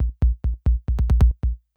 8 Boiling In Dust Kick Long.wav